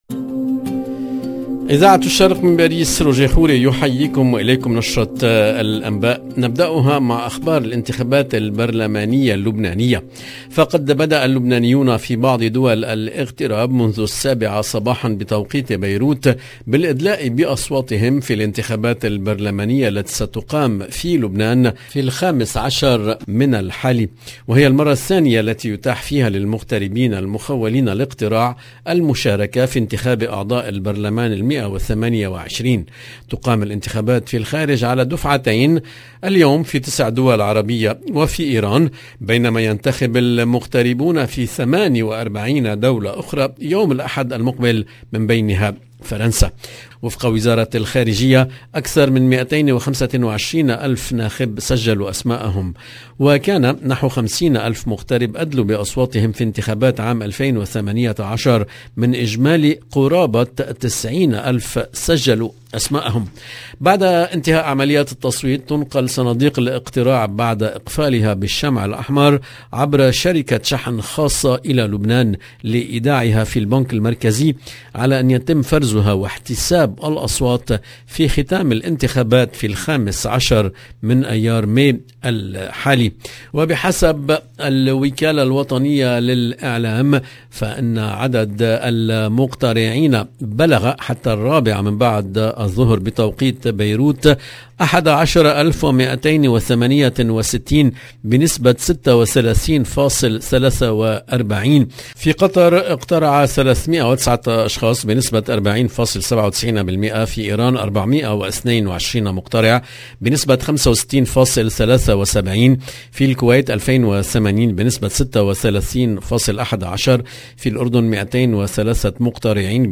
EDITION DU JOURNAL DU SOIR EN LANGUE ARABE DU 6/5/2022